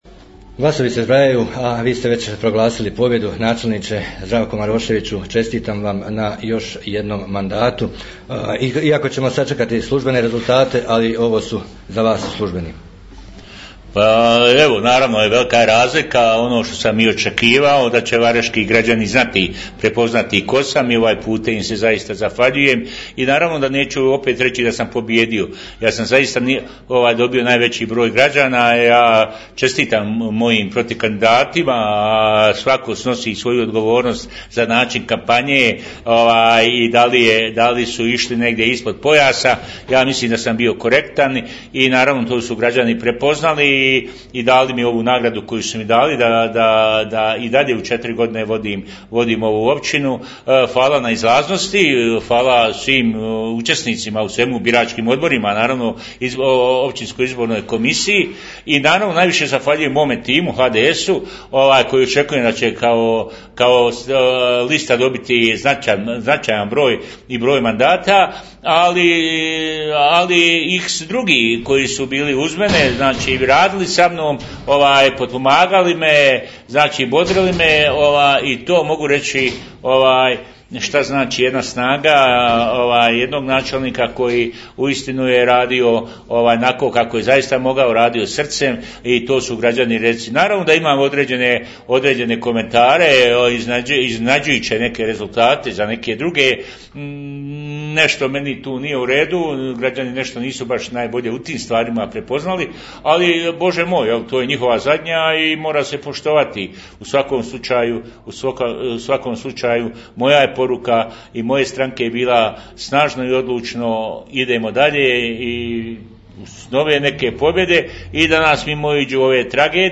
Poslušajte prvu izjavu načelnika Zdravka Maroševića nakon osvojenog trećeg mandata u Općini Vareš......